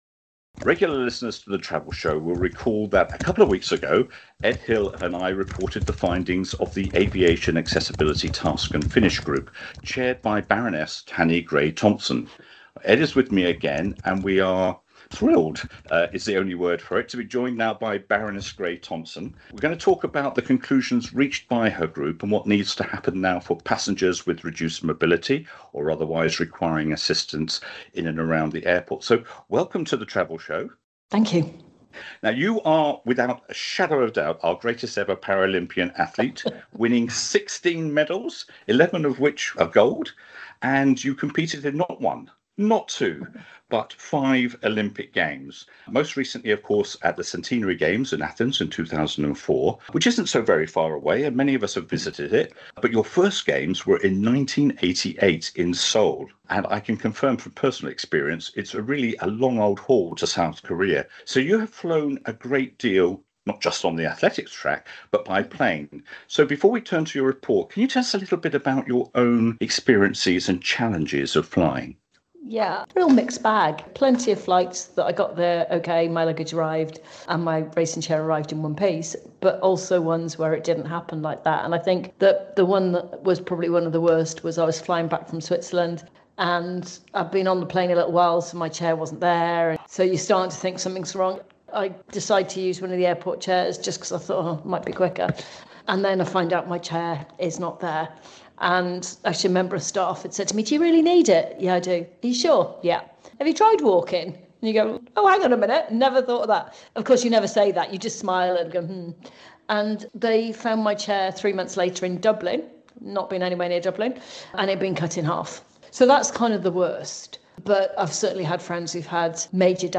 The Travel Show Interview with Baroness Tanni Grey-Thompson